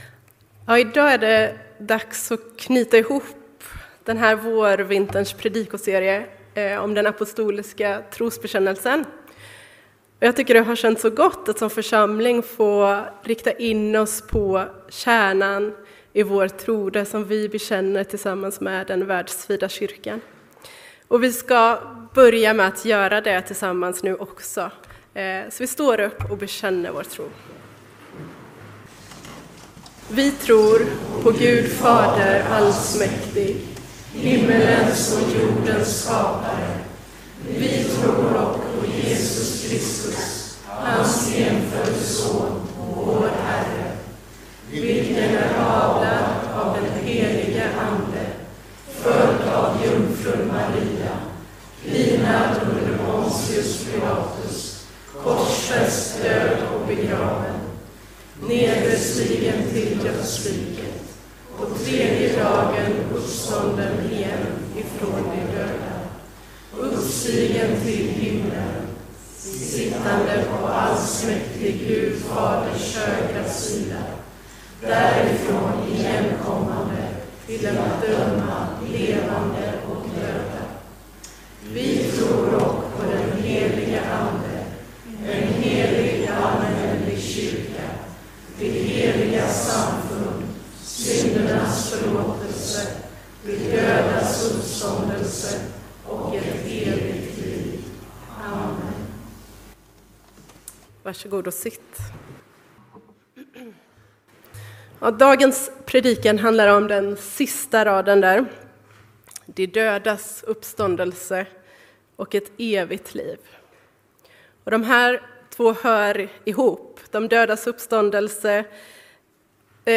Predikoserie: Vi tror (våren 2024)